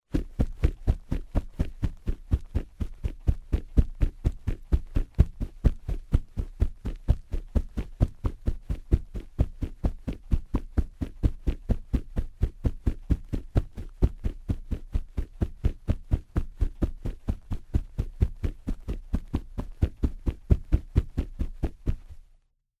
奔跑偏低频－YS070525.mp3
通用动作/01人物/01移动状态/土路/奔跑偏低频－YS070525.mp3
• 声道 立體聲 (2ch)